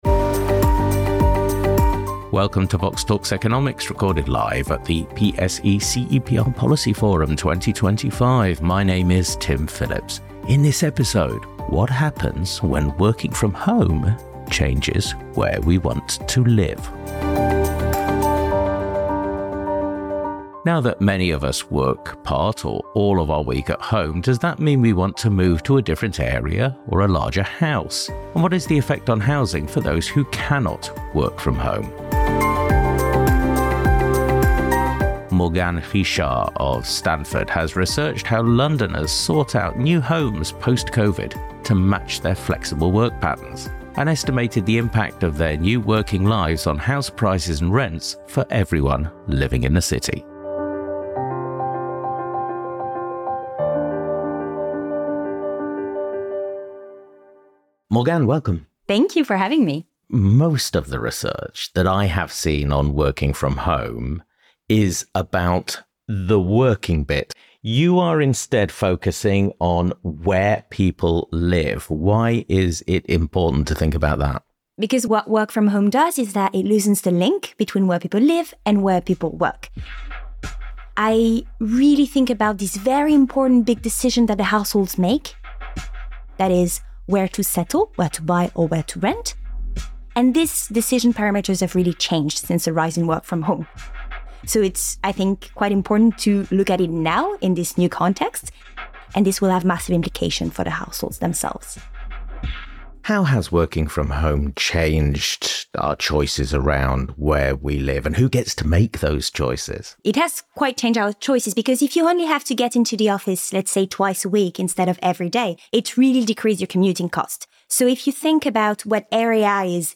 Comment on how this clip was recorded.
Recorded live at the PSE-CEPR Policy Forum 2025.